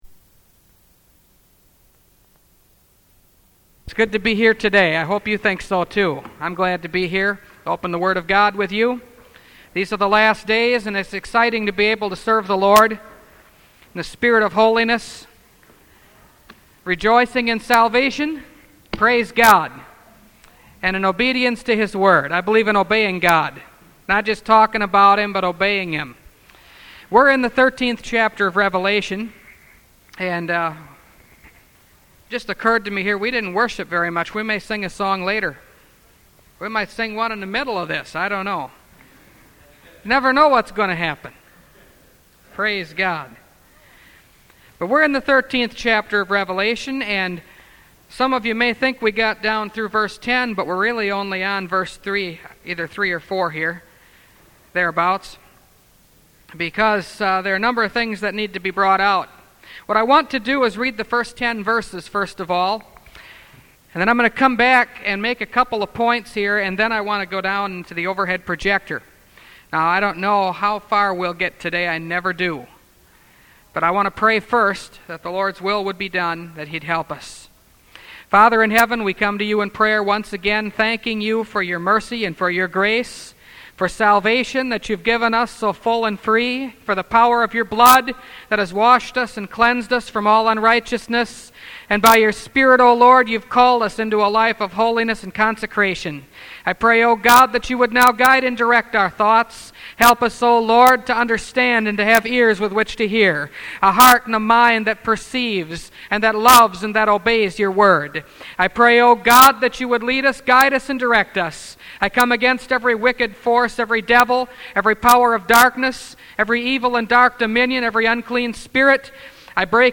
Revelation Series – Part 21 – Last Trumpet Ministries – Truth Tabernacle – Sermon Library